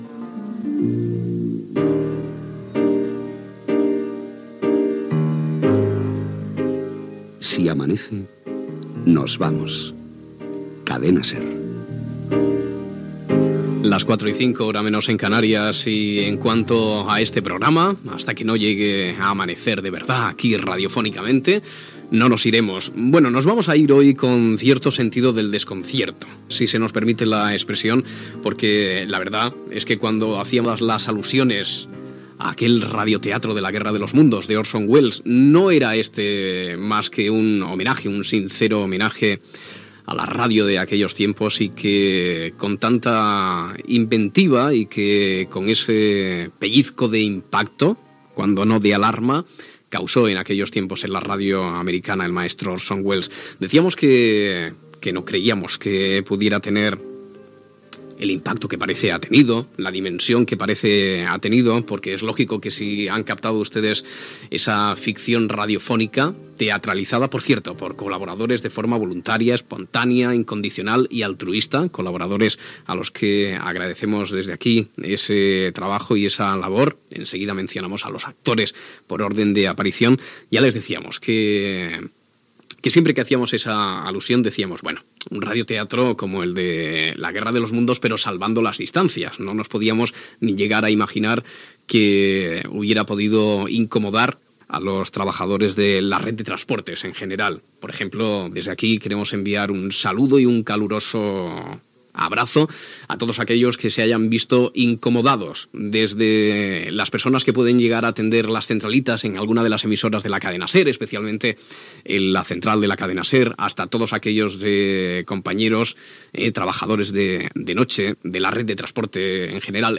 Comentari sobre què passaria al món si la tecnologia fallés. Indicatiu del programa. Ficció on es fa creure que tots els trens del país s'han quedat aturats.
Aclariment i participants en la ficció sonora de l'aturada de trens al país.